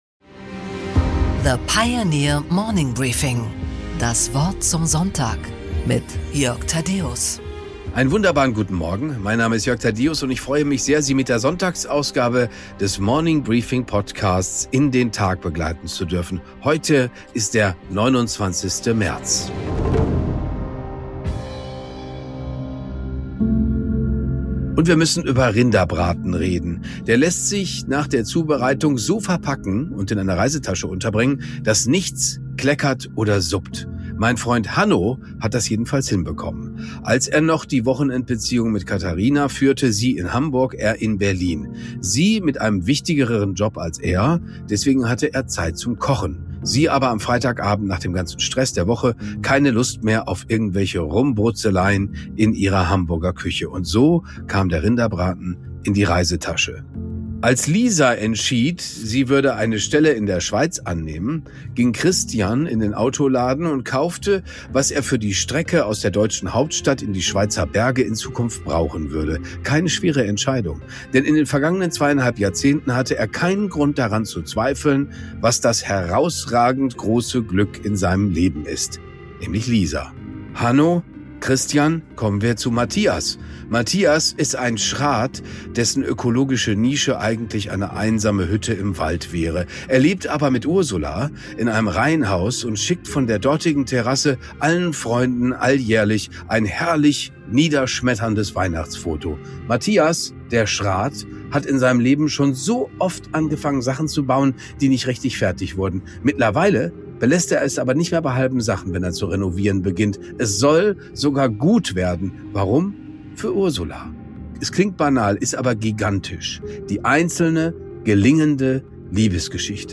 Jörg Thadeusz präsentiert die Morning Briefing Weekend Edition.